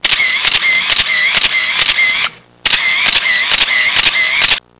Camera Sound Effect
Download a high-quality camera sound effect.
camera.mp3